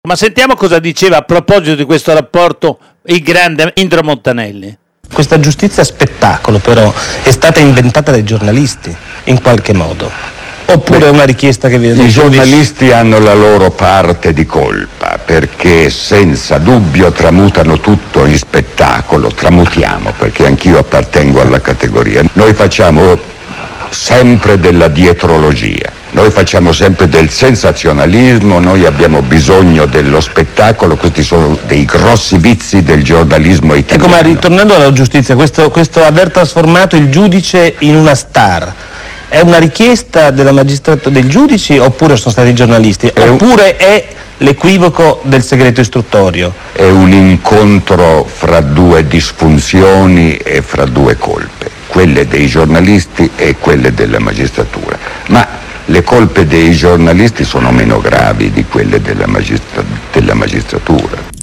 Minoli, coglie l’occasione dell’intervista a Palamara, per trasmettere alcuni passaggi di quella che fece nel 1985 a Indro Montanelli.
Un estratto dell’intervista